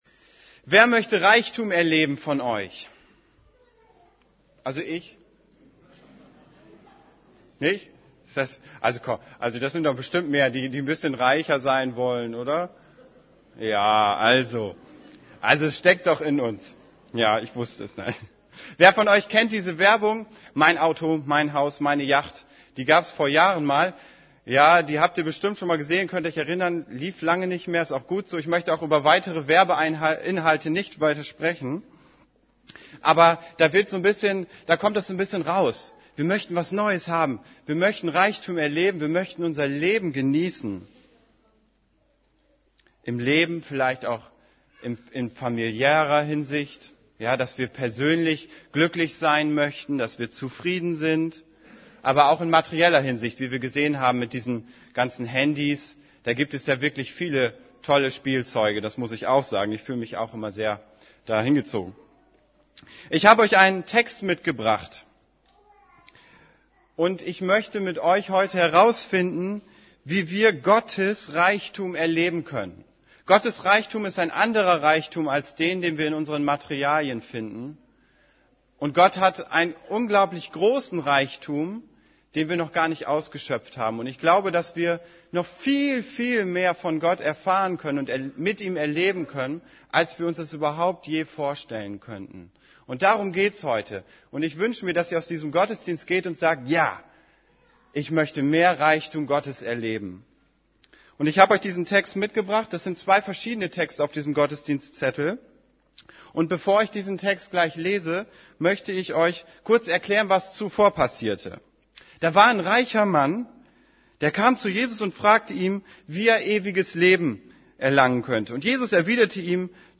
Predigt vom 19.